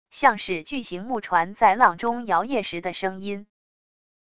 像是巨型木船在浪中摇曳时的声音wav下载